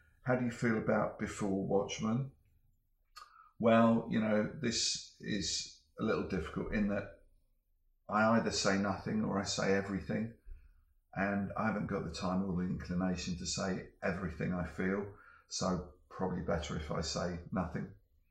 Dave Gibbons interview: How do you feel about Before Watchmen?